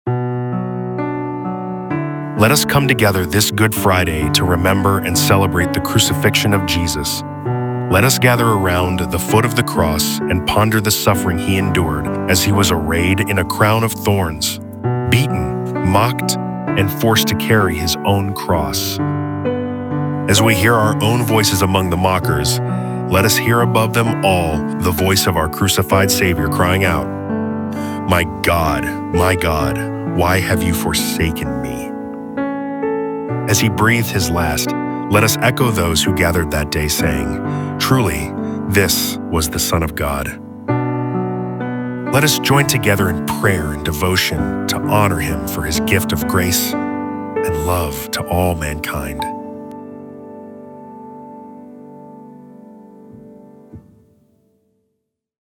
CallToWorship_GoodFriday_PIANO.mp3